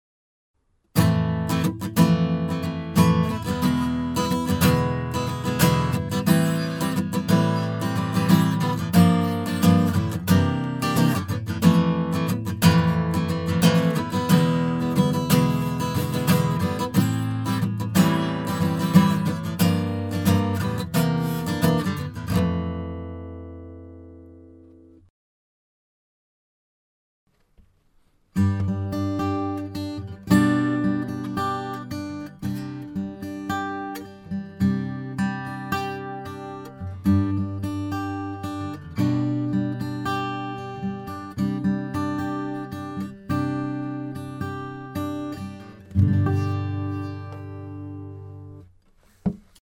歌が得意ではないので、今回はアコースティックギターを録音してみました。
試聴用のオーディオは、前半にストローク、後半にアルペジオが入っています。 まずはコンプなしを聴いてみましょう。
雑な感じが出てて内心「もうちょっとうまく弾けよ..と思う演奏ですがこれくらいバラつきがあった方がコンプの効果がわかりやすいかと。w